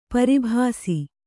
♪ pari bhāsi